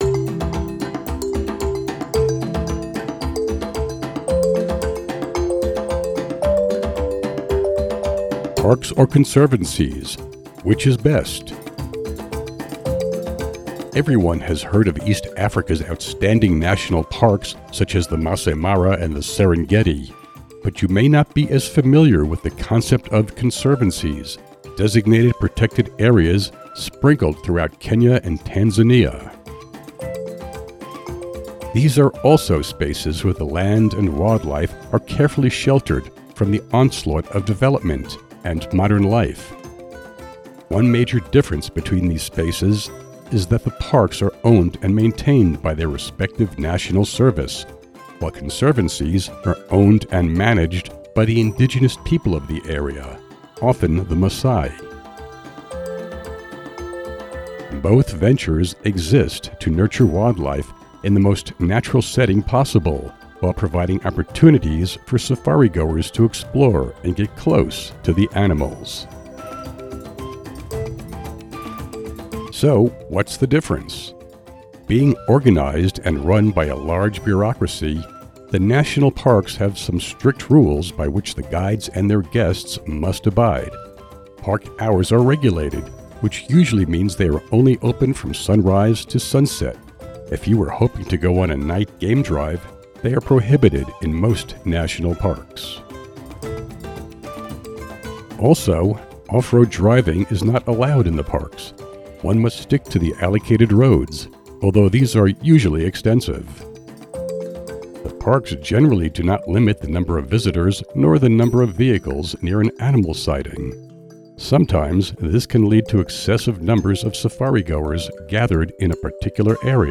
Listen to an audio version of this blog post!